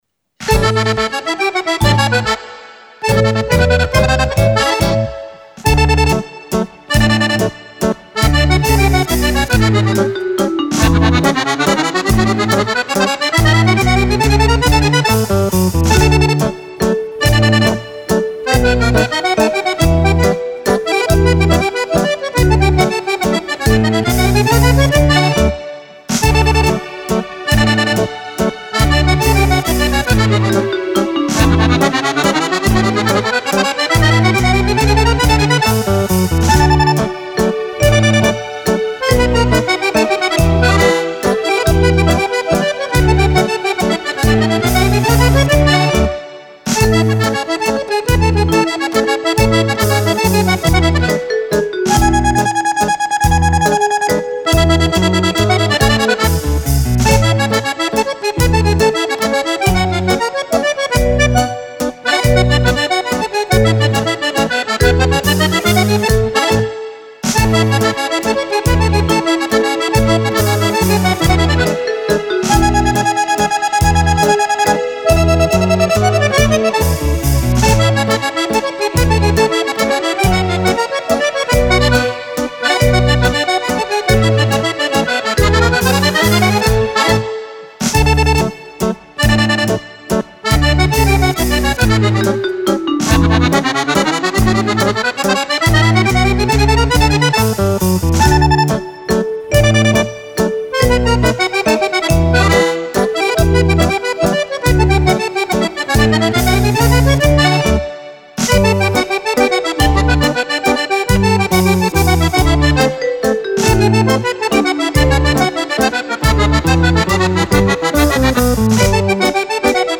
Mazurka
Album di ballabili  per Fisarmonica.